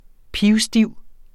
Udtale [ ˈpiwˈsdiwˀ ]